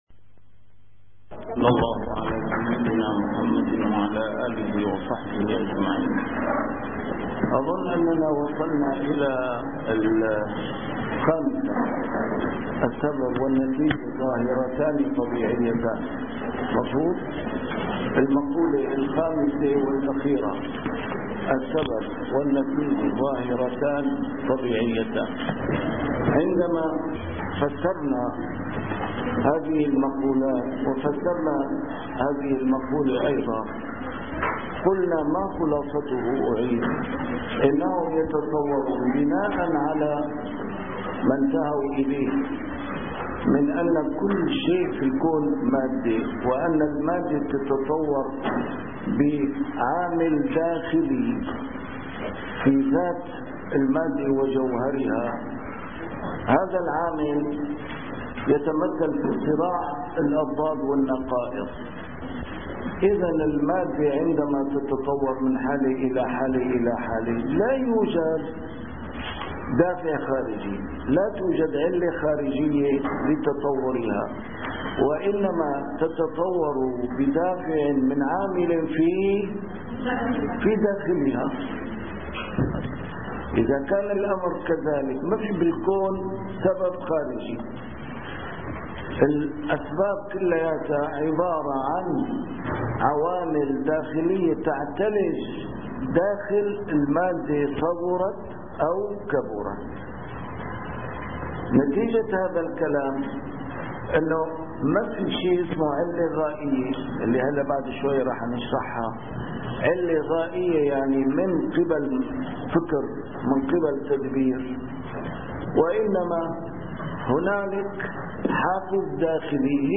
المحاضرة الثالثة: تتمة نقد المادة الجدلية ثم التعرف على المادية التاريخية ونقدها 1